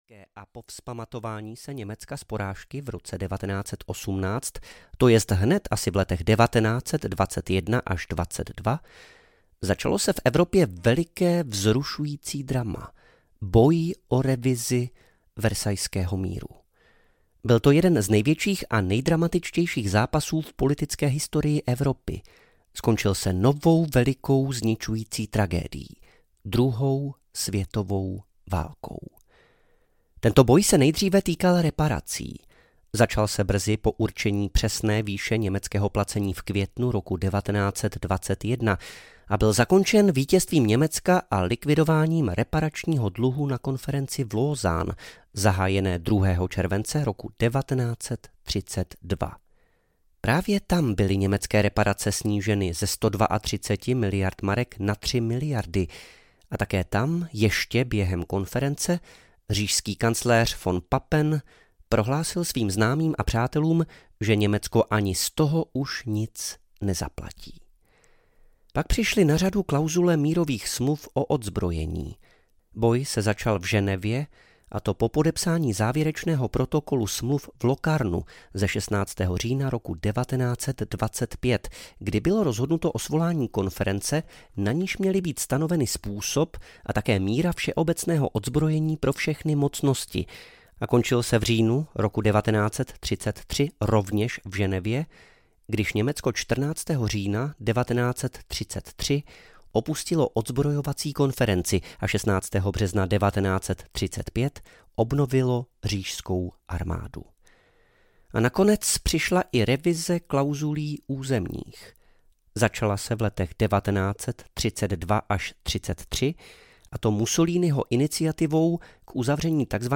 Paměti - část 1: Před velkou bouří audiokniha
Ukázka z knihy